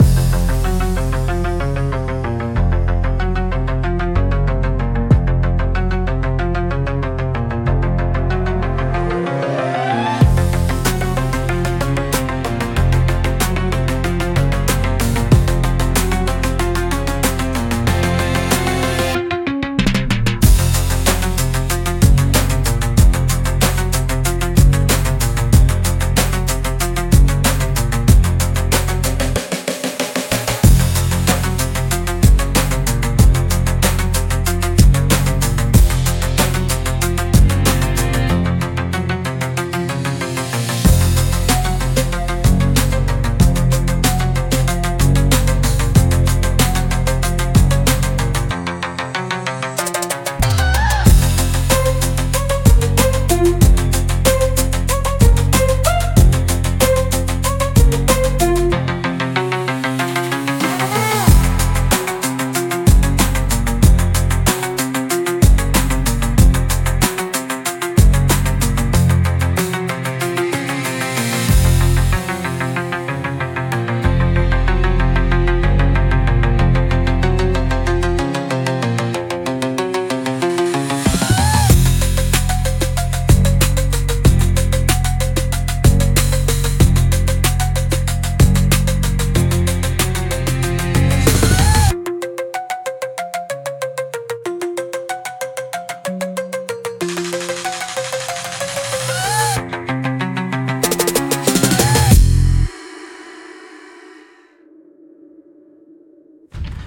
Instrumental - Overdrive - 1.46 mins